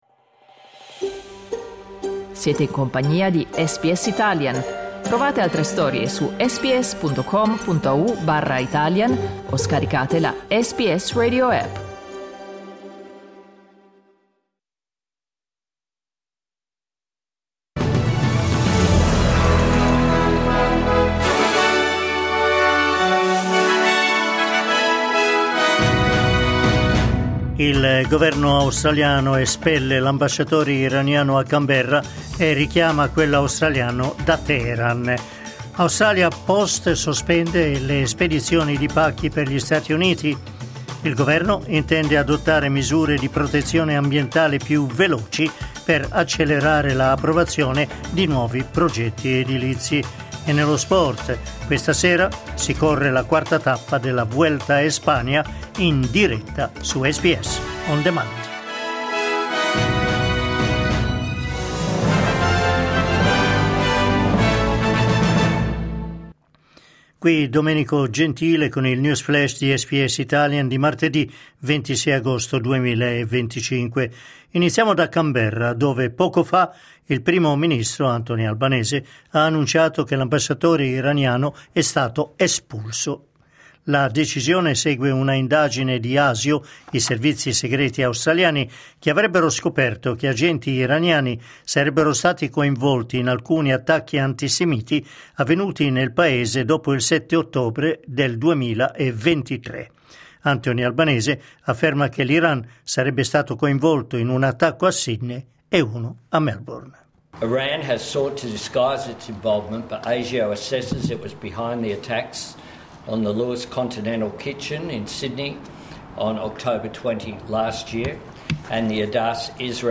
News flash martedì 26 agosto
L’aggiornamento delle notizie di SBS Italian.